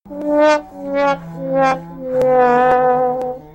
Womp Womp Womp